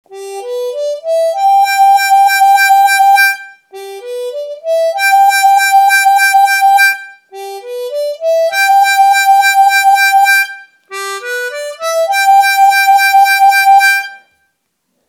Сыграть музыкальную фразу -2-3-4+5+6_ _ _ _ _ _ _, делая эффект вау-вау в конце музыкальной фразы.